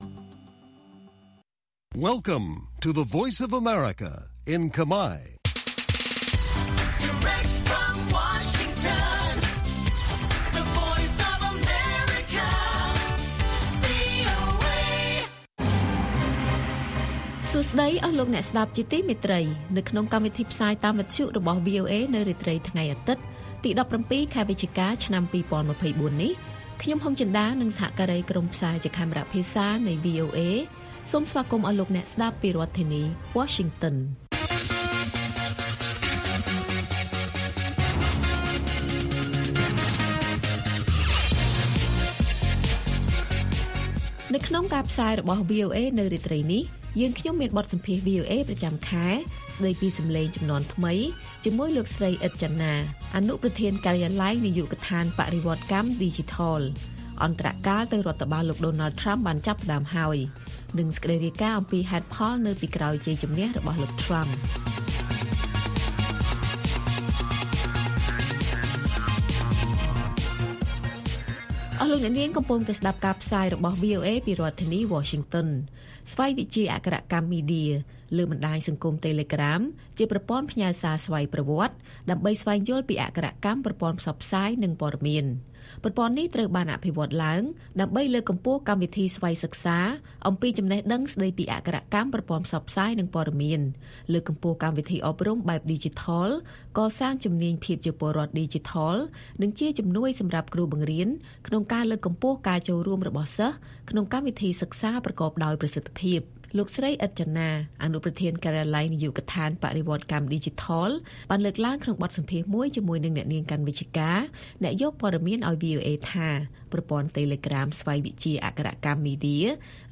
ព័ត៌មានពេលរាត្រី
បទសម្ភាសន៍ VOA